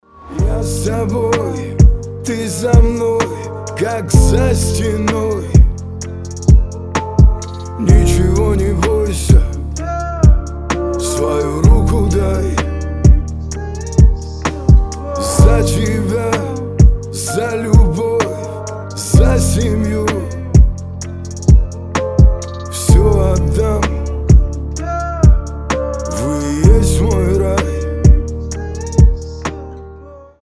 • Качество: 320, Stereo
душевные
спокойные
медленные